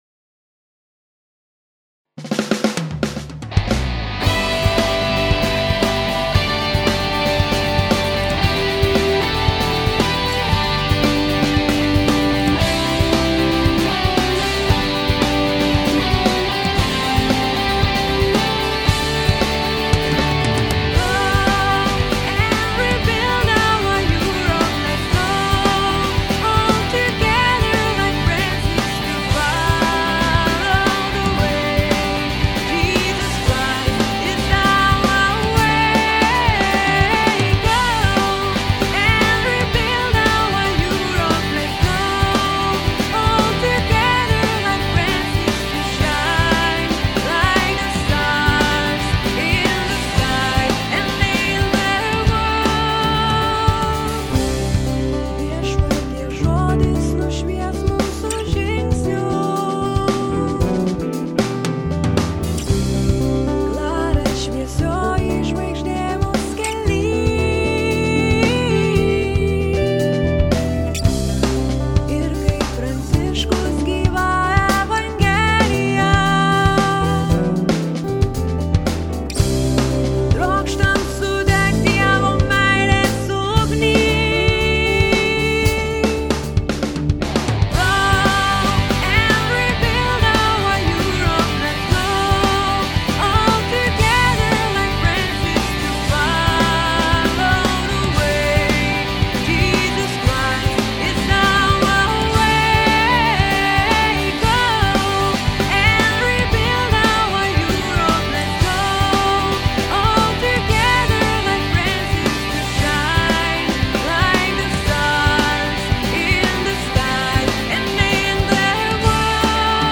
Hymna stretnutia.